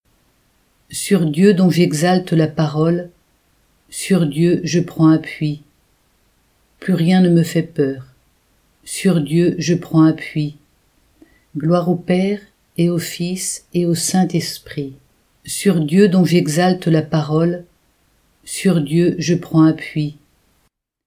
Repons-Sur-Dieu-dont-j-exalte-la-parole-R.mp3